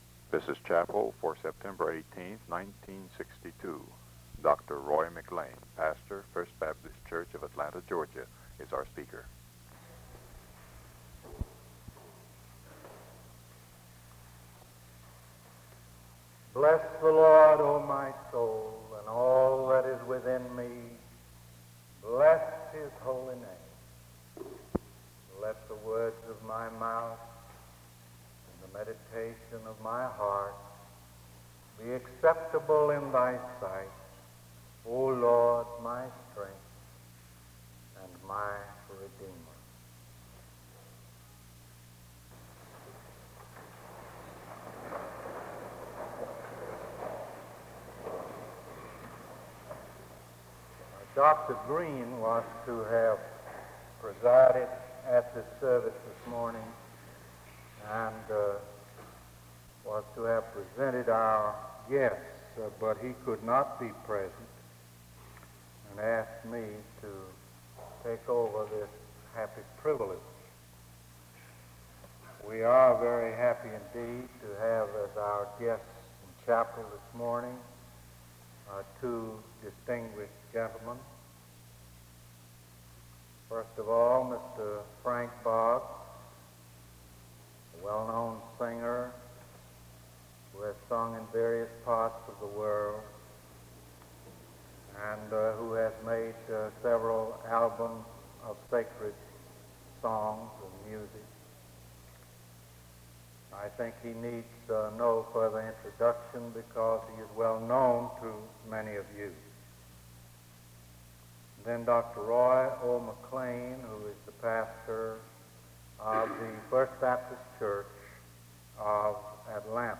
The service begins with a prayer and introduction from 0:16-4:53. A special performance is sung from 5:00-7:40.